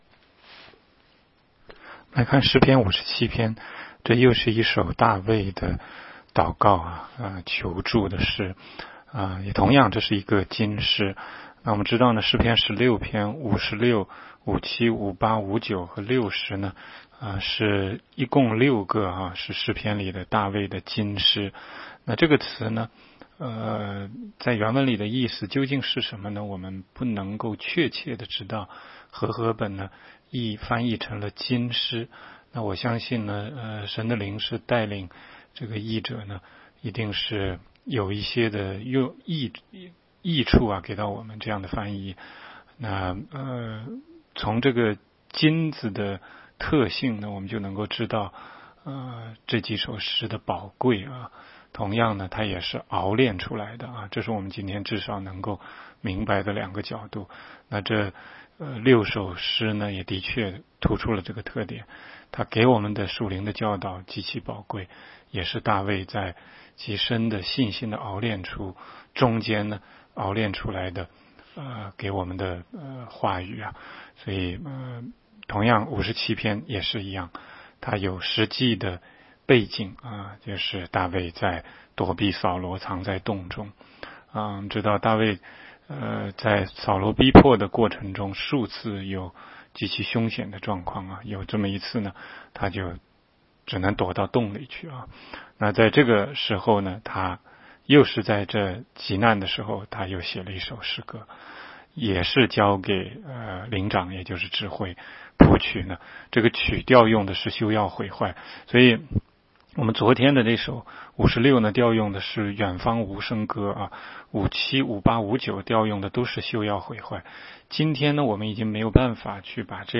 16街讲道录音 - 每日读经-《诗篇》57章